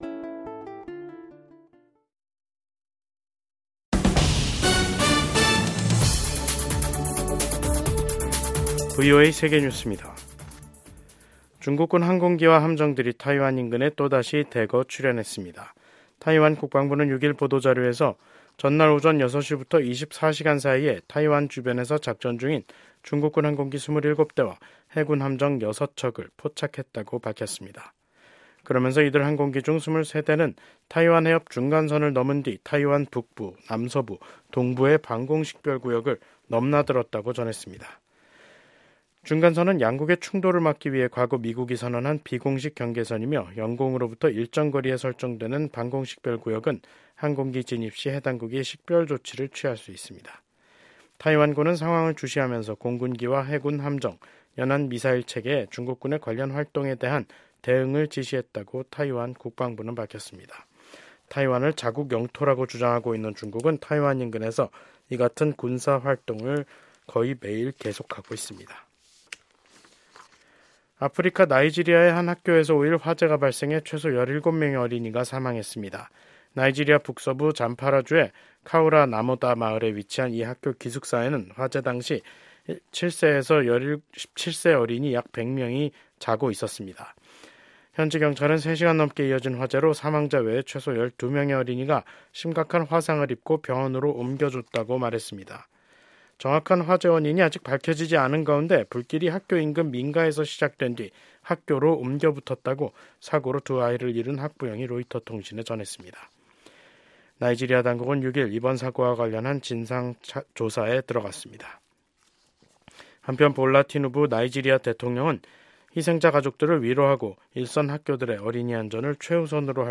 VOA 한국어 간판 뉴스 프로그램 '뉴스 투데이', 2025년 2월 6일 2부 방송입니다. 미국의 도널드 트럼프 행정부 출범으로 냉랭했던 북중 관계에 일정한 변화가 나타날 수 있다는 관측이 제기됩니다. 미국 의회에서 코리아코커스 공동의장을 맡고 있는 의원들이 트럼프 행정부가 들어서면서 한국이 미국과 에너지 협력을 확대하는 데 유리한 환경이 조성됐다는 초당적인 입장을 밝혔습니다.